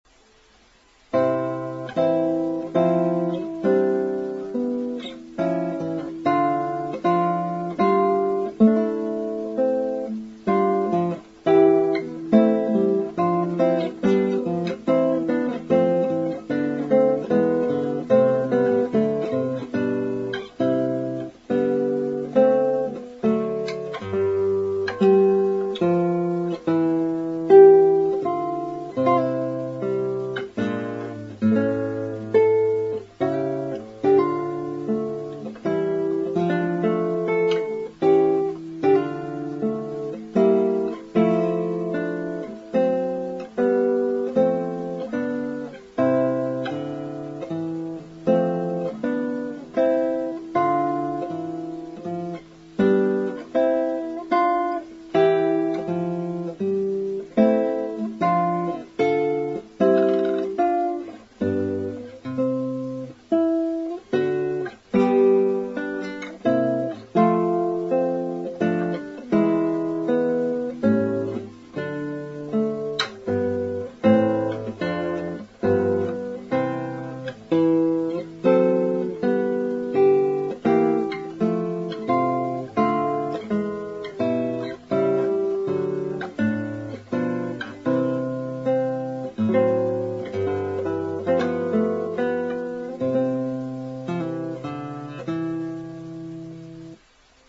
新愛器での初めての録音。